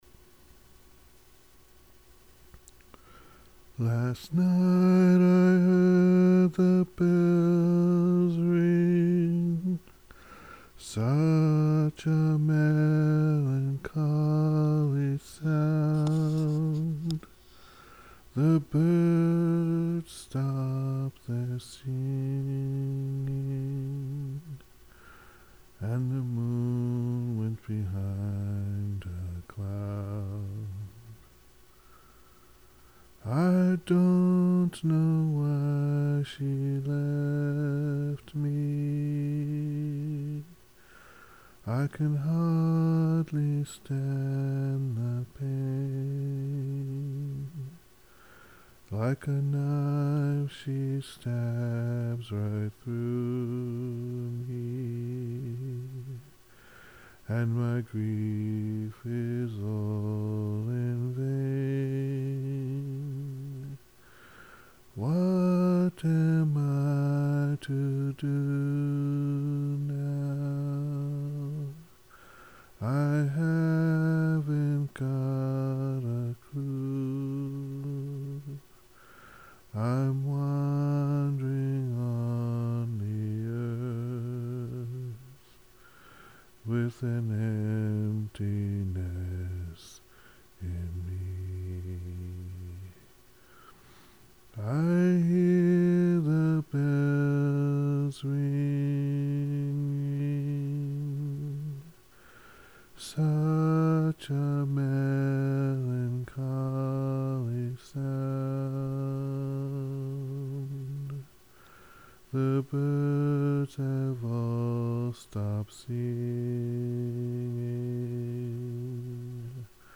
Voice recording #1. Such A Melancholy Sound